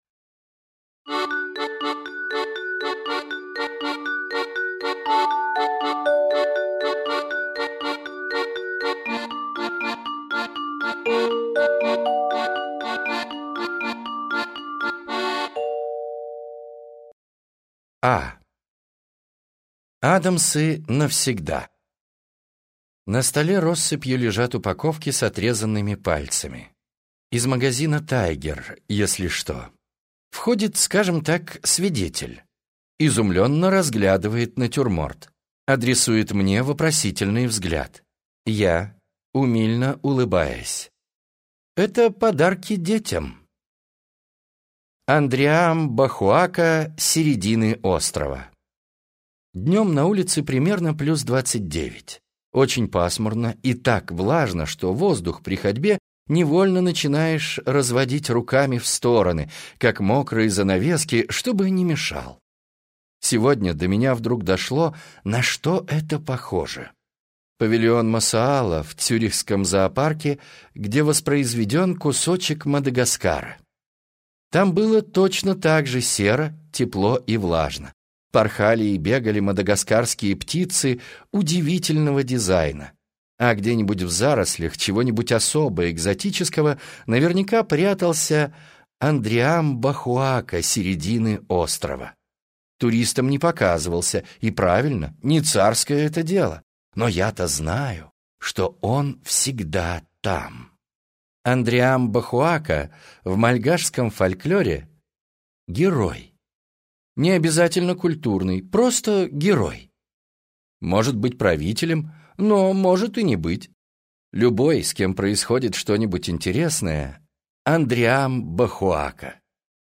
Аудиокнига Небesное, zлодея | Библиотека аудиокниг